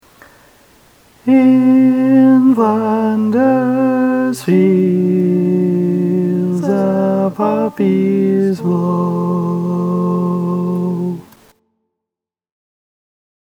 Key written in: F Major
Nice gentle 4-part suspension-chord tag